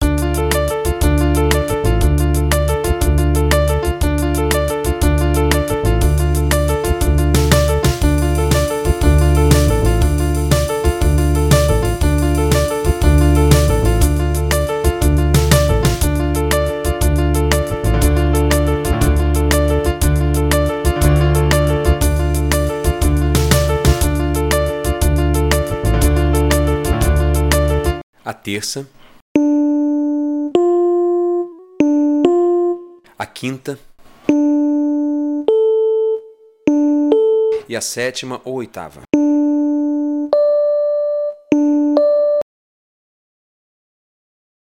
Você vai ouvir o acorde tocado por uma banda e, em seguida, nó vamos desmembrá-lo nas notas que o compôe.